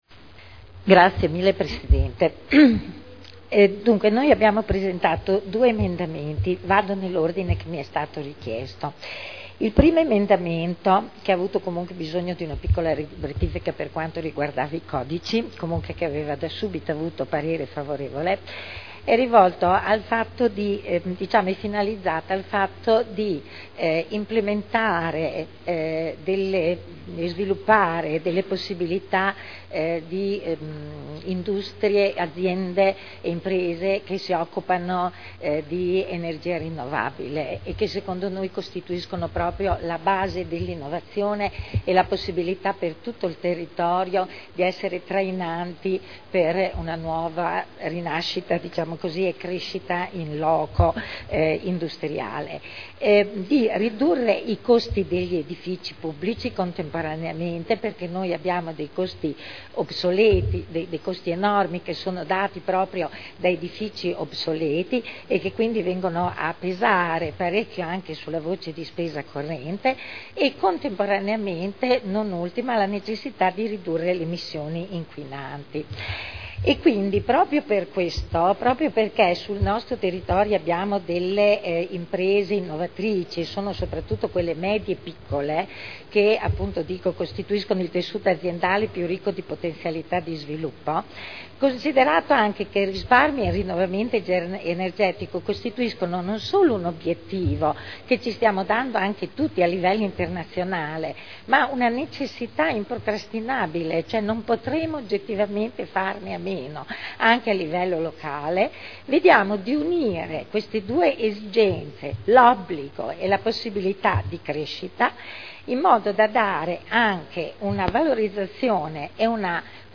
Eugenia Rossi — Sito Audio Consiglio Comunale